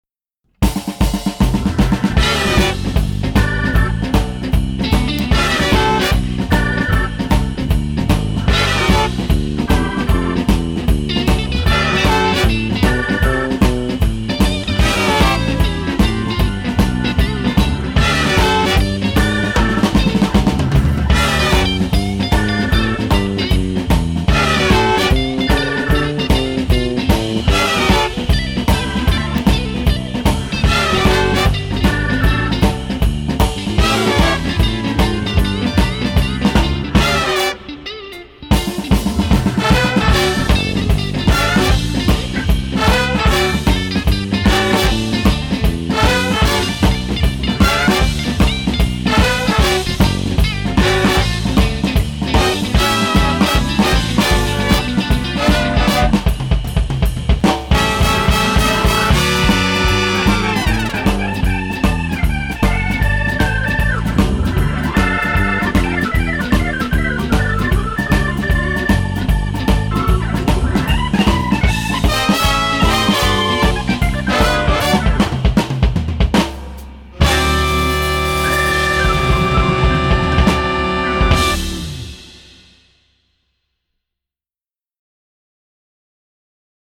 PLAY ALONG: SHUFFLE
Texas Shuffle
with Drums: